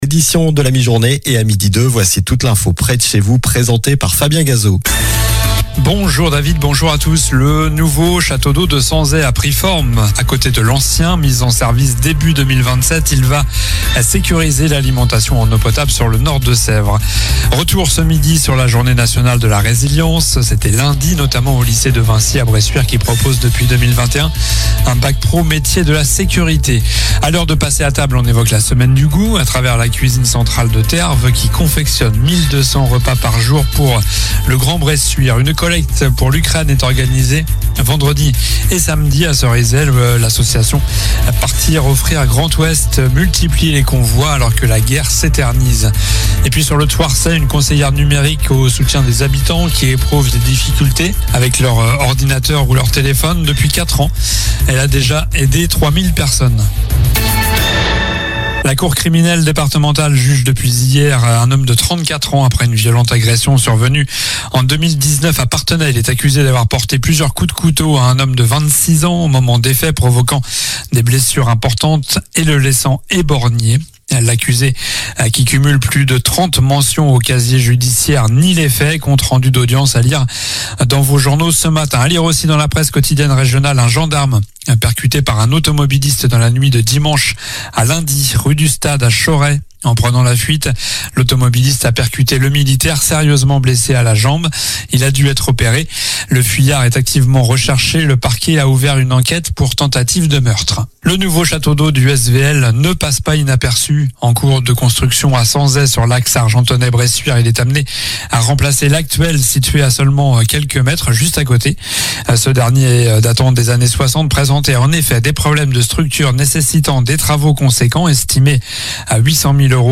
COLLINES LA RADIO : Réécoutez les flash infos et les différentes chroniques de votre radio⬦
Journal du jeudi 15 octobre (midi)